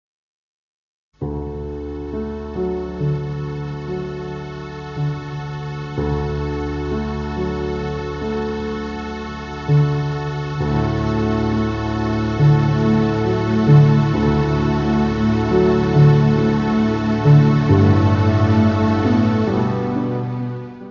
Área:  Novas Linguagens Musicais